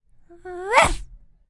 描述：a small dog barks in the stairs, a woman says something. Olympus LS10 internal mics
标签： barking dog fieldrecording
声道立体声